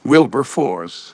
synthetic-wakewords
ovos-tts-plugin-deepponies_Discord_en.wav